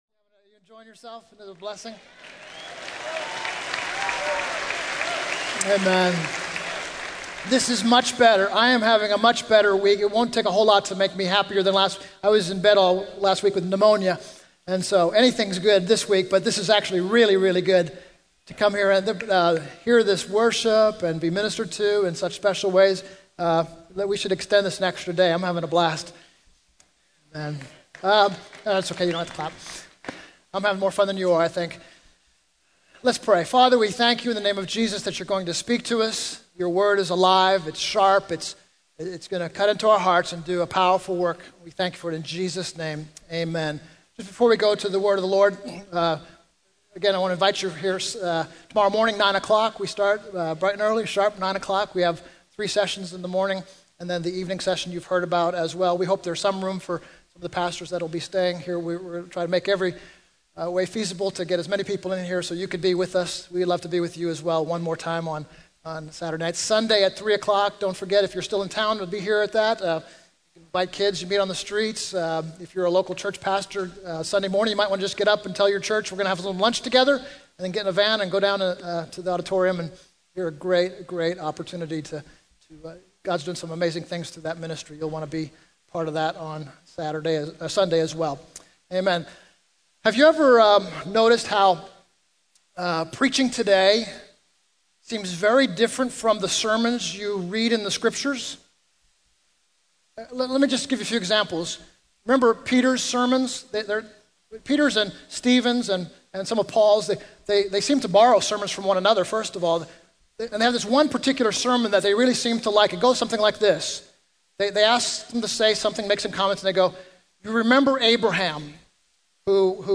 In this sermon, the preacher discusses the history of God and the exile that the Old Testament people of God went through. He starts by referencing the story of Adam and Eve in the Garden of Eden, where God walked and talked with them. The preacher then mentions various biblical figures such as Abraham, Isaac, Jacob, Moses, and the prophets, highlighting key events in their lives.